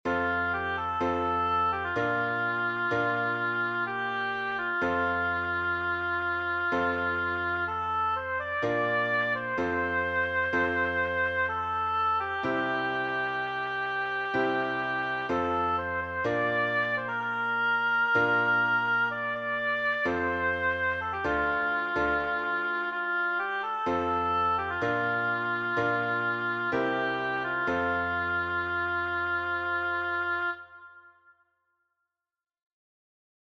African American hymn